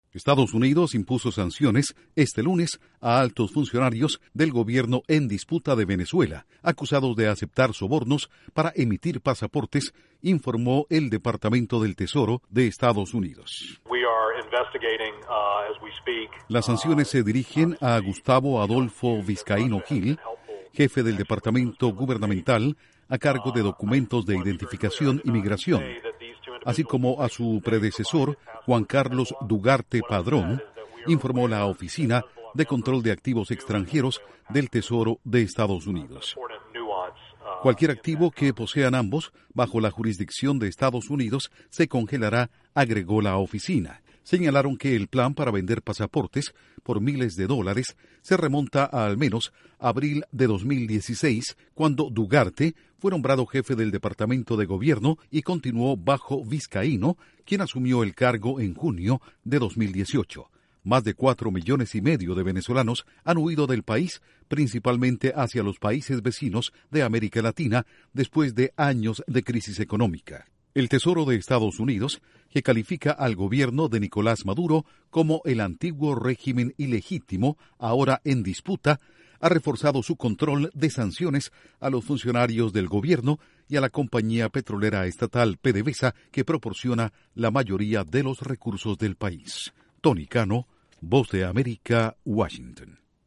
Estados Unidos sanciona a funcionarios de gobierno en disputa de Venezuela por vender pasaportes. Informa desde la Voz de América en Washington